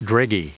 Prononciation du mot dreggy en anglais (fichier audio)
Prononciation du mot : dreggy